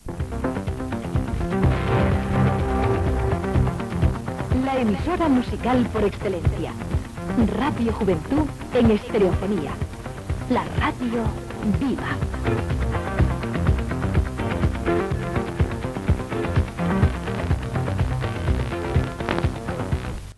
Indicatiu de l'emissora en estereofonia